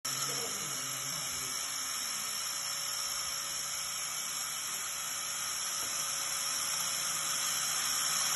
Grinder video 2.mp4